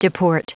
Prononciation du mot deport en anglais (fichier audio)
Prononciation du mot : deport